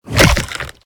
combat / abilities / bite / flesh2.ogg
flesh2.ogg